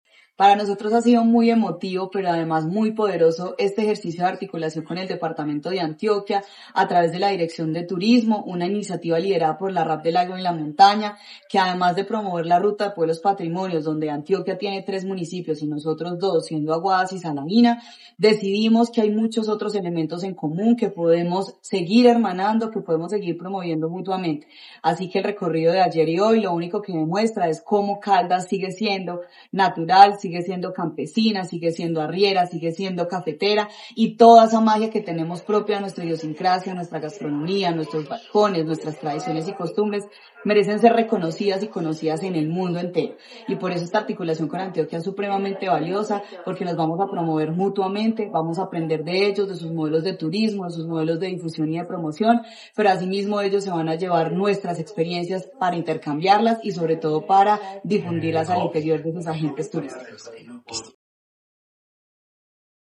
Juanita Espeleta, secretaria de Desarrollo, Empleo e Innovación de Caldas
secretaria-de-Desarrollo-Empleo-e-Innovacion-de-Caldas-Juanita-Espeletamp3.mp3